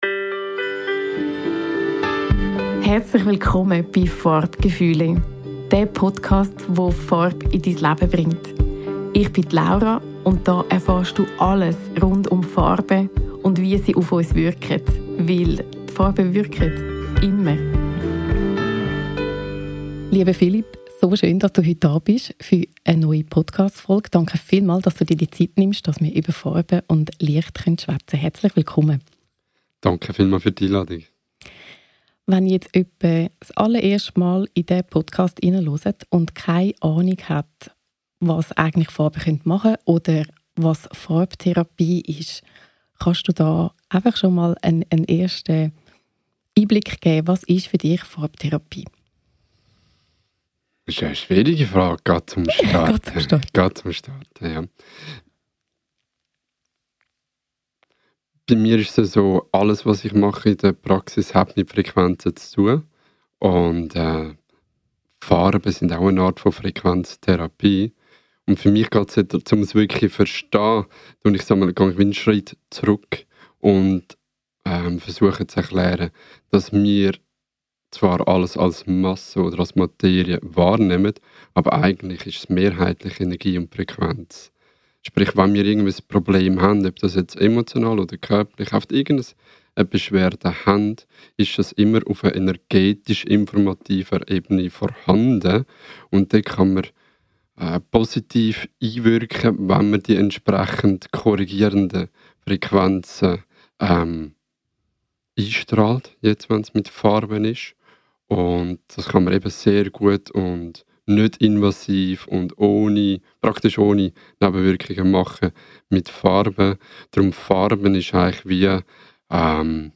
#18 Interview